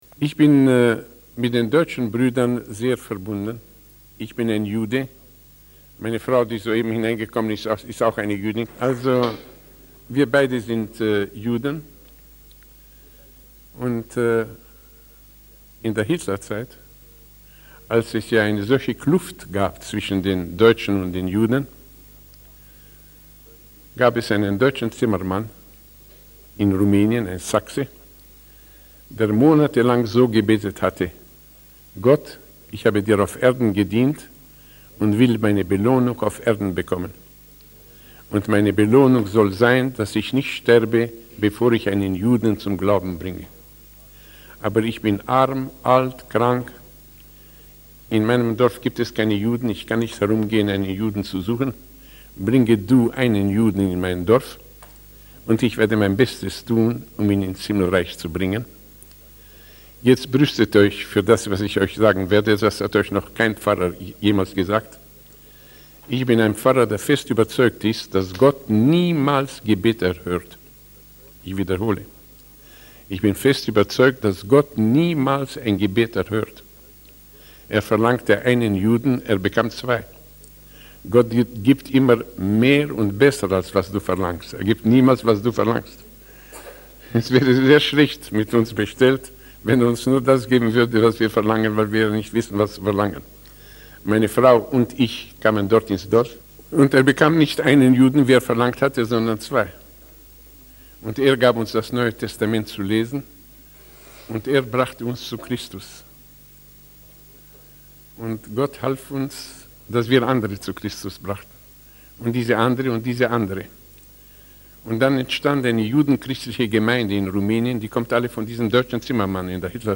In this sermon, the preacher discusses the horrific conditions in a prison in Guinea, where prisoners were forced to kill live mice.